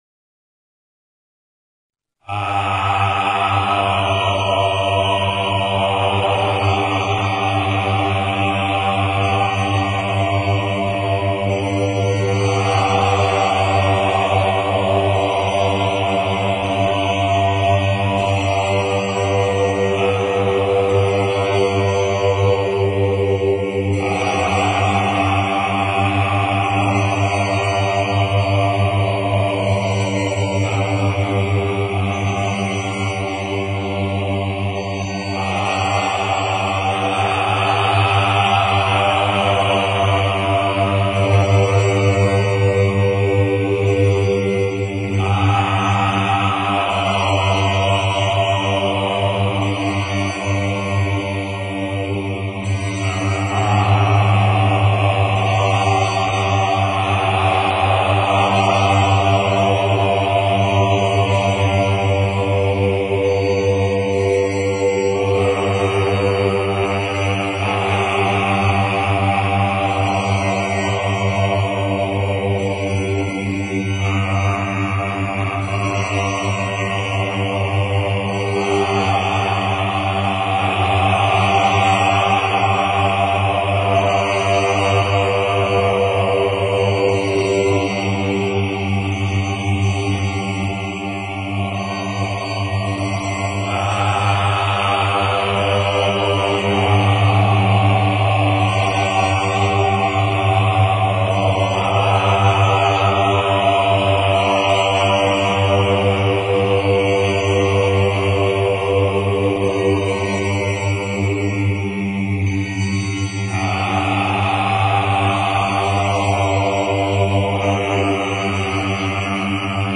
AUM Mantra 440.mp3